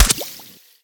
hitwater2.mp3